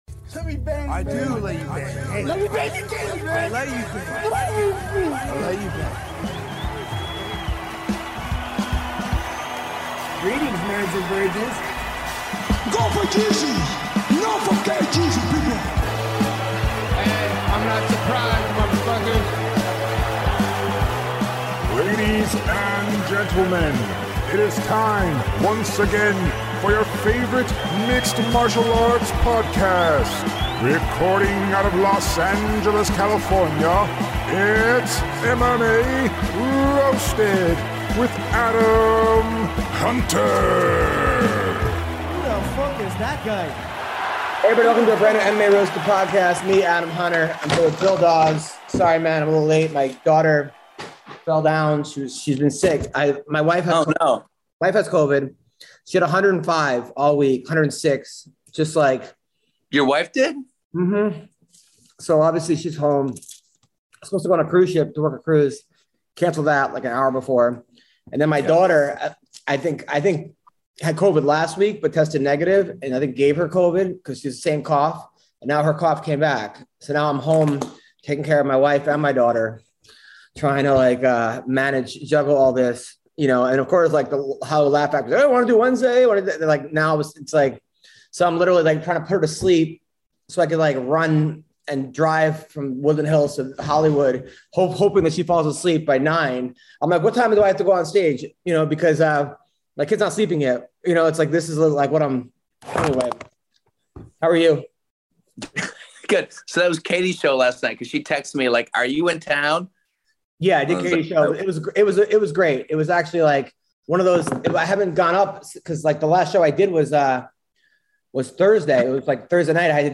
MMA fighters